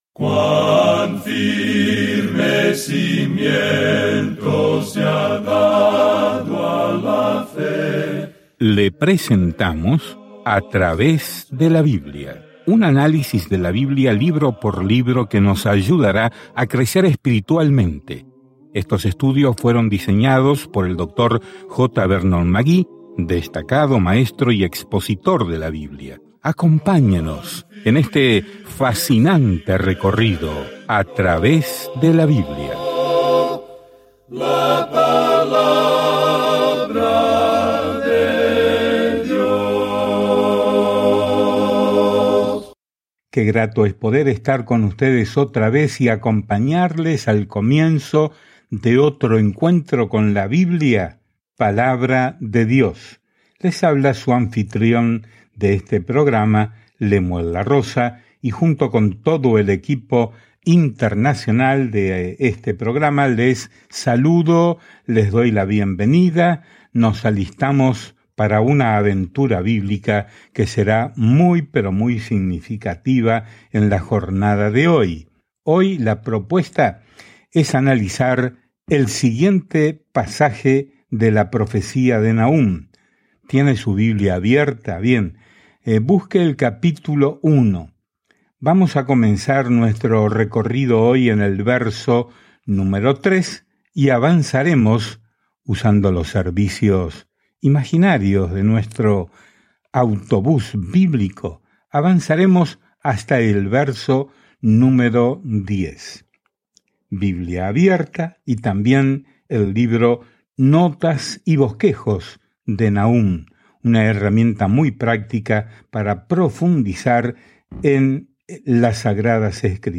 Escrituras NAHUM 1:3-10 Día 2 Comenzar este Plan Día 4 Acerca de este Plan Nahum, cuyo nombre significa consuelo, trae un mensaje de juicio a los enemigos de Dios y trae justicia y esperanza a Israel. Viaje diariamente a través de Nahum mientras escucha el estudio de audio y lee versículos seleccionados de la palabra de Dios.